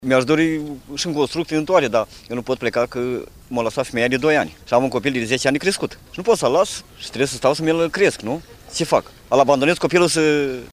Un  bărbat a declarat că îşi crește singur copilul în vârstă de 10 ani, după ce soția l-a părăsit și nu îşi poate abndona fiul pentru a merge la serviciu: